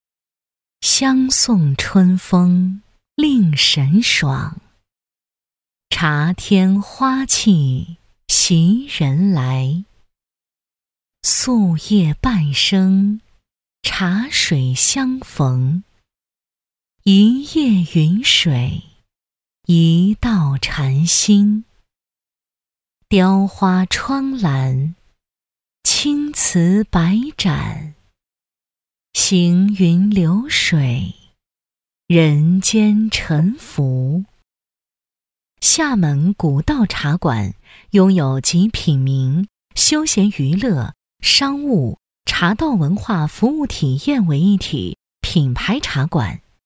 女16-纪录片【古道茶馆-娓娓道来】
女16-纪录片【古道茶馆-娓娓道来】.mp3